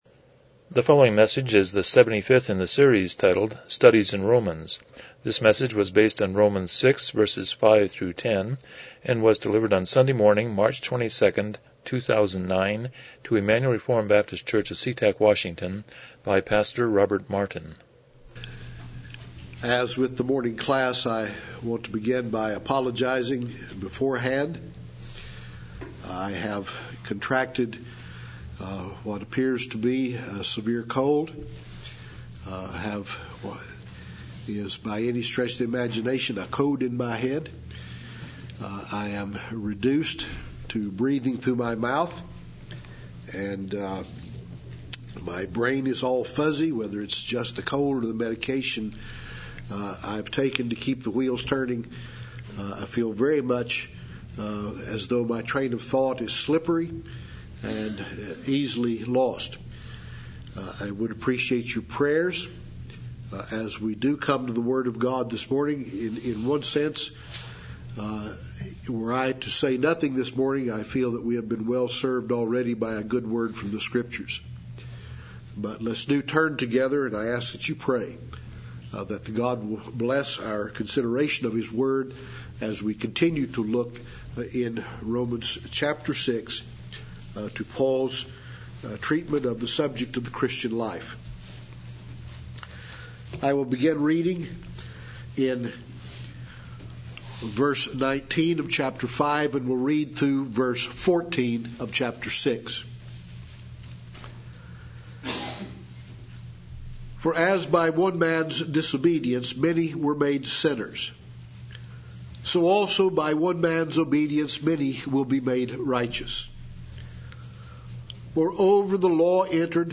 Passage: Romans 6:5-10 Service Type: Morning Worship